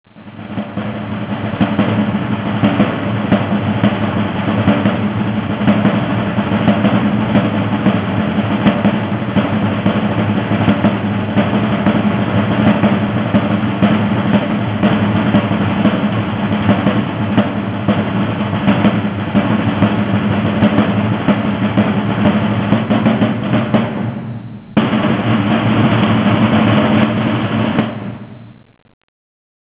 here for Beat to Quaters-Drill without powder. The number of last rolls of the drum told if it was a drill with or without loading blanks and/or shot, or with 3 rolls, this is no drill.
roll.wav